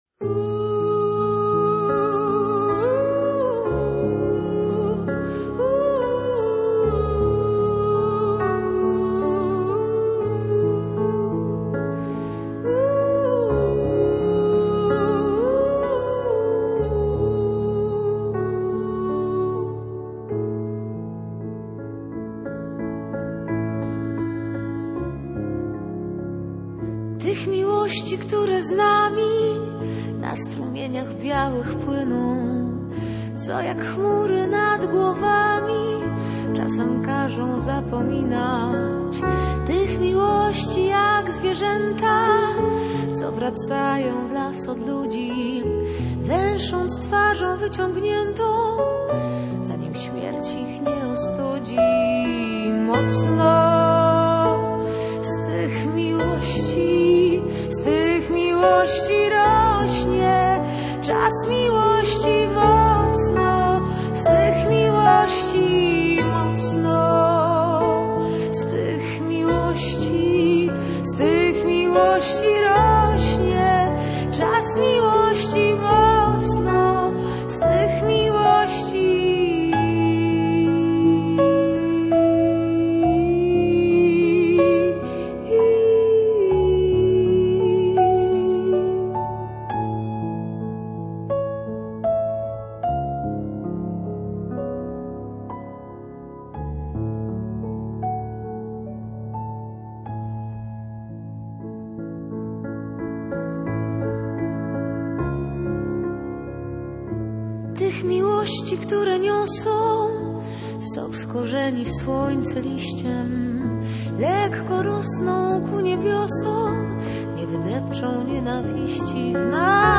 Jak wyjaśniła Mela Koteluk w Muzycznej Strefie Radia Gdańsk, propozycja nagrania płyty na podstawie wierszy Baczyńskiego wyszła z Muzeum Powstania Warszawskiego.